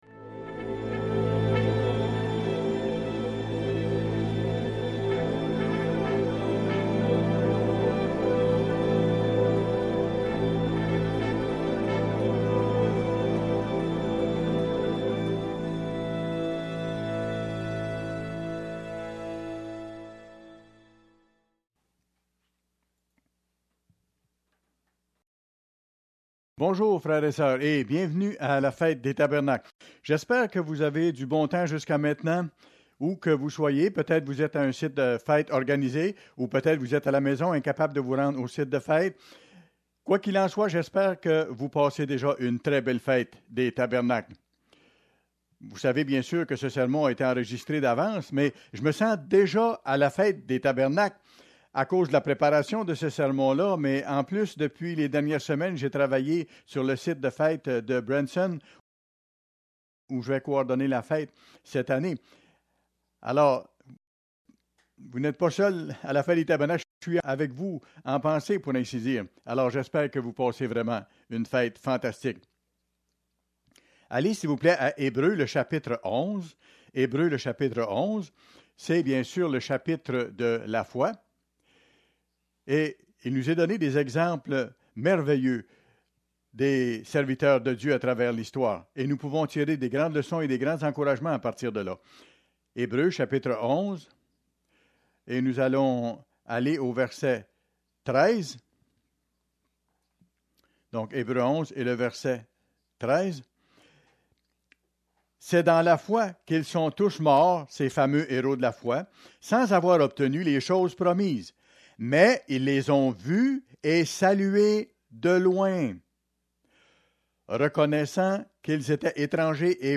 Fête des Tabernacles, 4ème jour